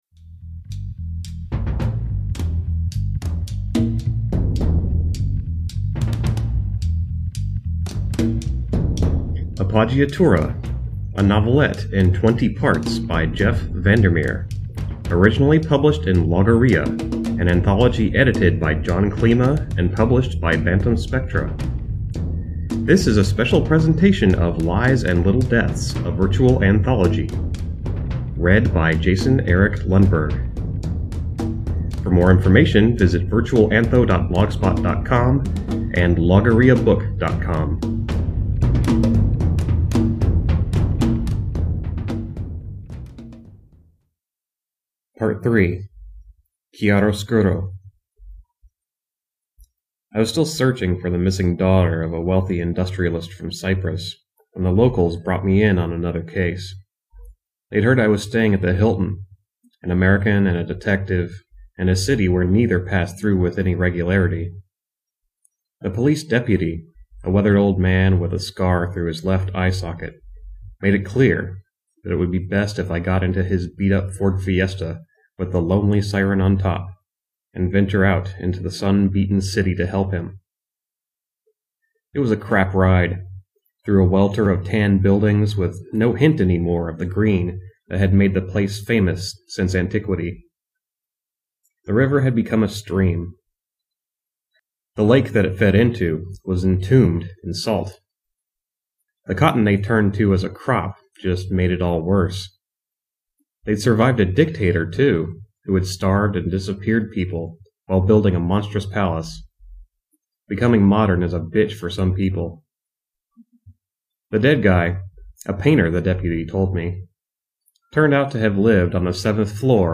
Music provided by The Church, and is used with permission.